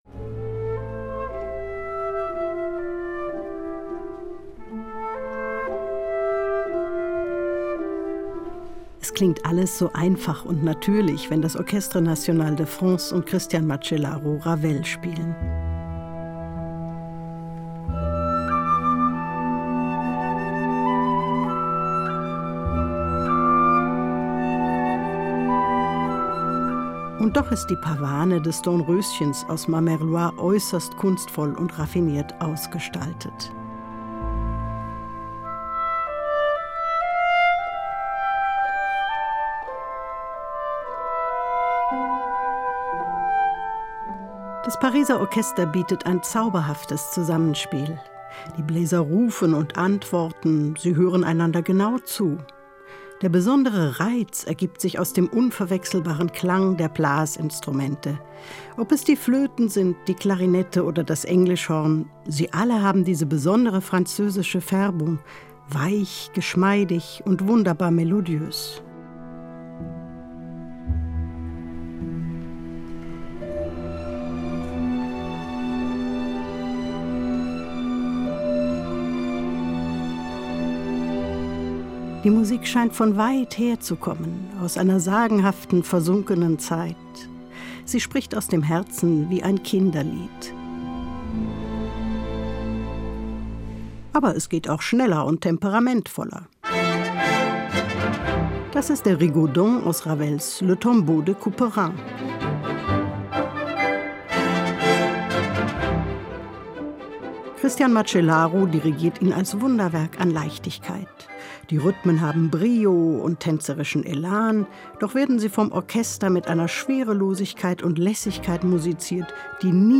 Zauberhaftes Zusammenspiel mit französischer Färbung
Die Bläser rufen und antworten, sie hören einander genau zu.